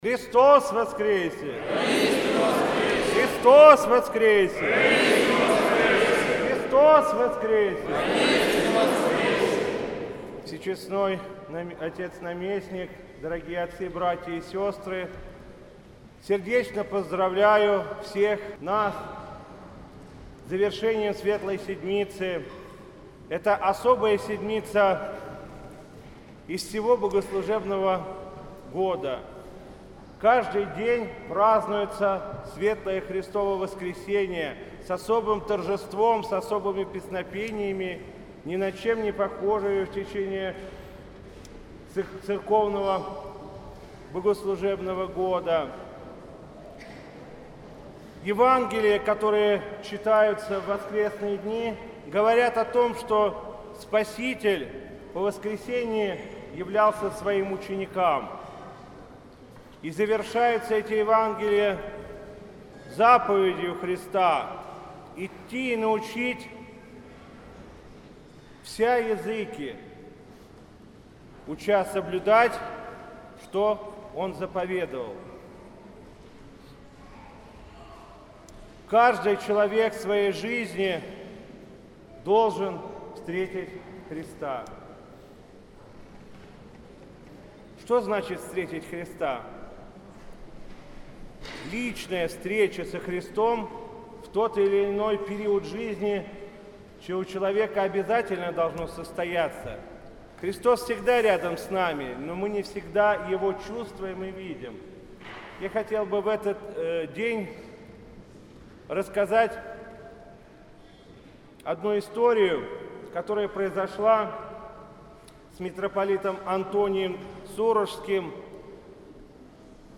Затем владыка Игнатий поздравил присутствующих со светлым праздником Пасхи и обратился к ним с архипастырским словом.
Слово после Литургии в Светлую седмицу